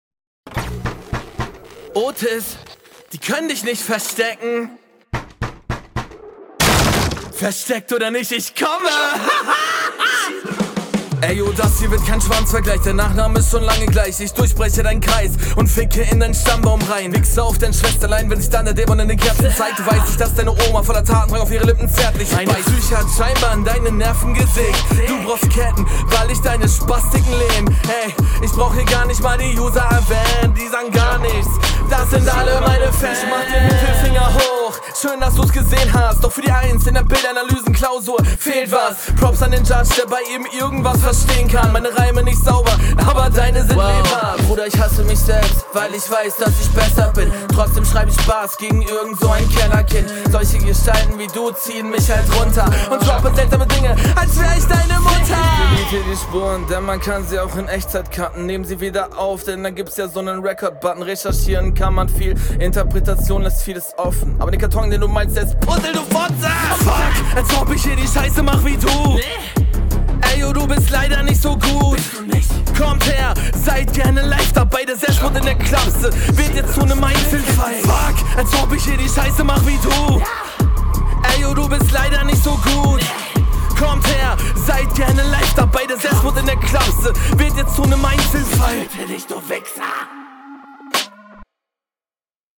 Die Stimme ist eigentlich auch cool, aber das Lispeln stört ein wenig.